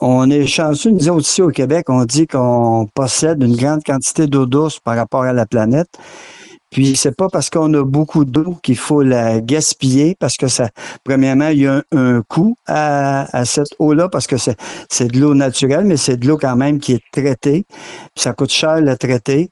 En entrevue, le maire a rappelé l’importance de faire attention à cette richesse naturelle.